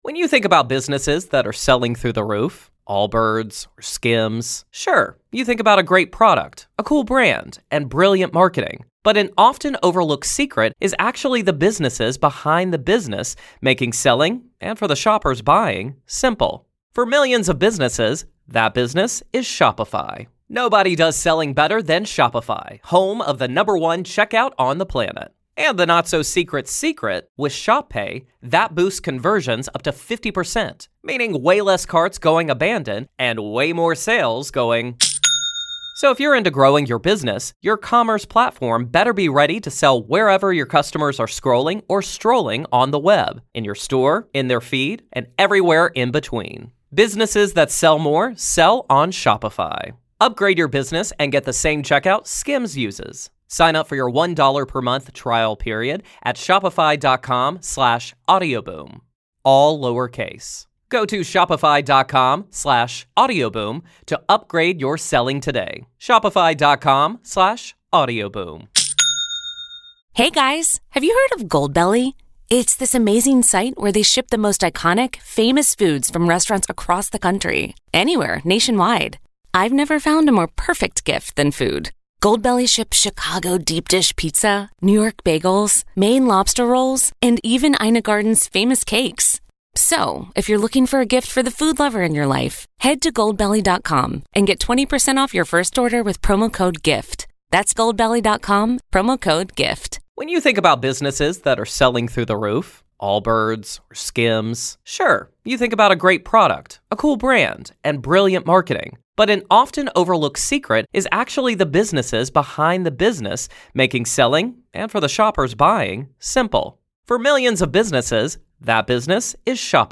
A listener calls in with a truly chilling tale that began after her grandmother passed away in the family home.